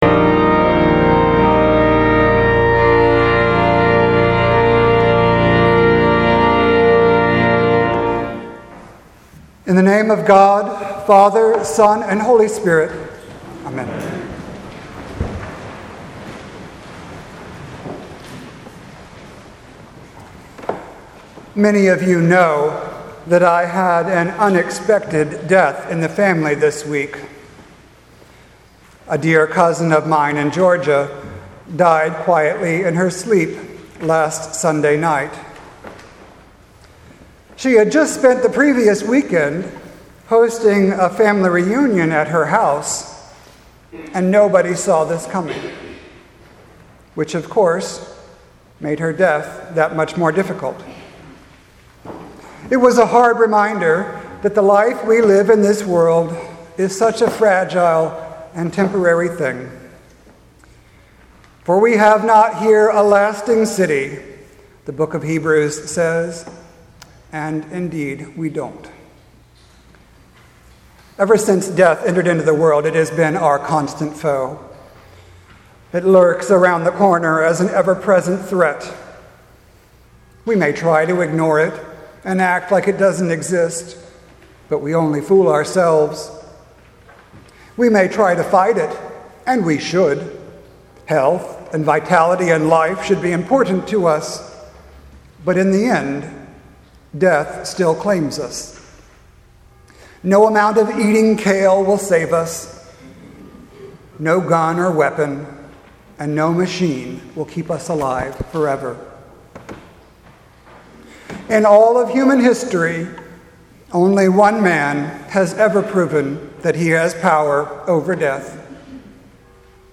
Sermon for All Saints’ Sunday, November 5th 2017